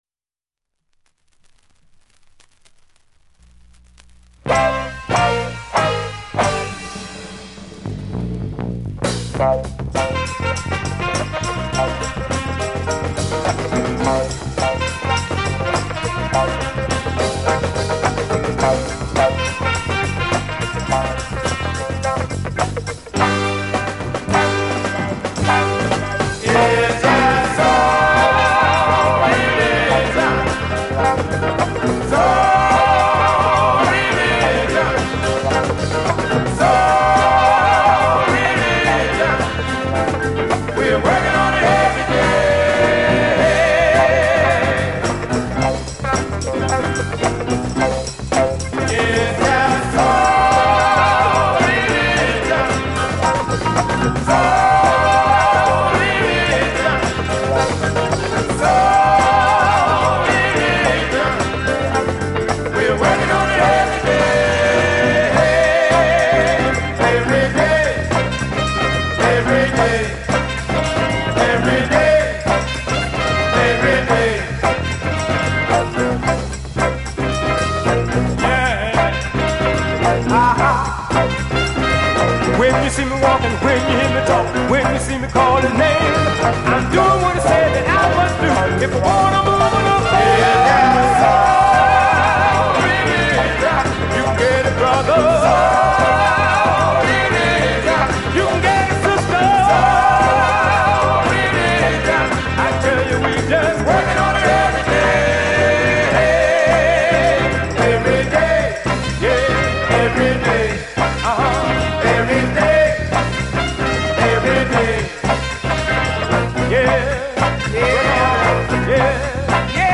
もとはゴスペルグループのようだが、 この曲はファンク度１０００パーセント。 みんな知らないファンク４５シングル。